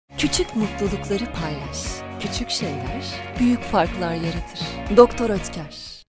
Deep, Natural, Cool, Warm, Corporate
Commercial